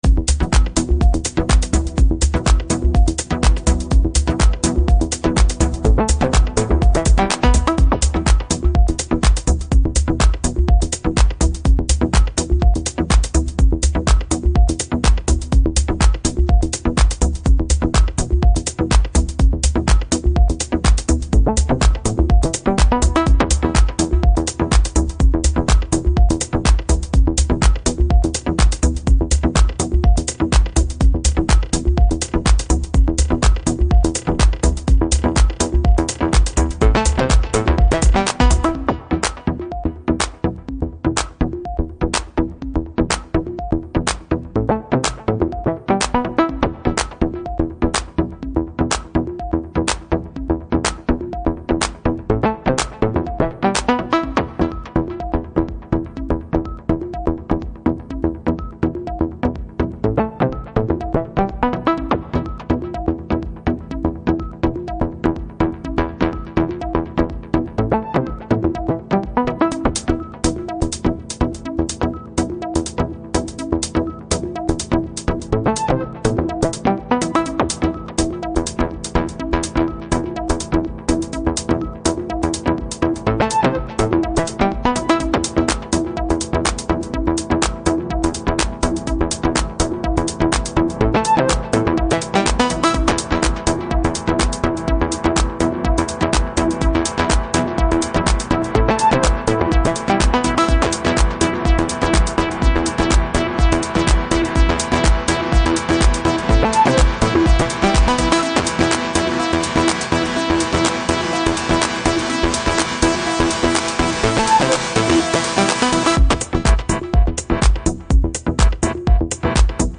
deadpan minimal house groover